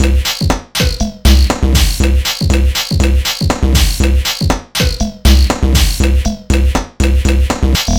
Here’s a little 120BPM 1-bar loop for testing/demo purposes, which was resampled/precisely recorded on the Tonverk.
I’ve made a 4 bar loop out of it and added a few trigs in between and changed the LFO DEP on subsequent PAGES.
The result is nothing too exciting as I didn’t want to go too crazy so it’s supposed to serve as a simple “proof-of-concept” only :slight_smile: